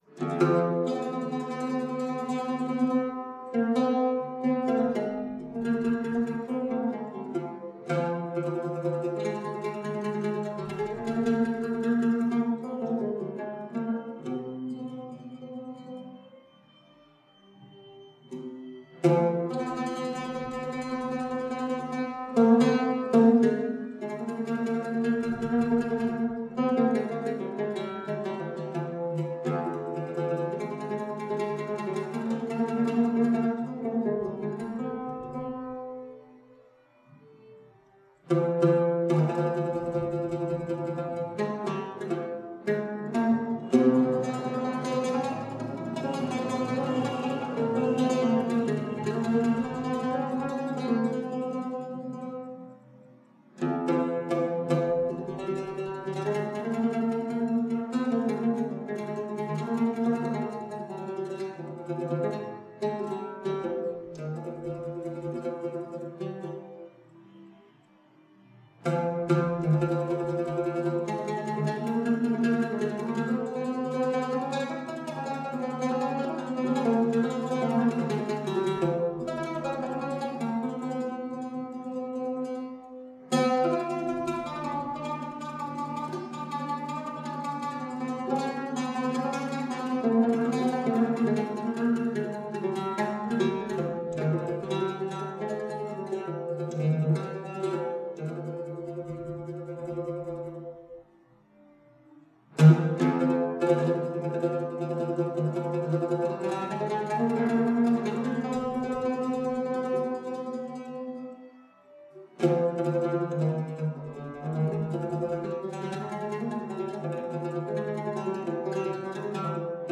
ラースト・カビール旋法アザーンの旋律（ウードによる）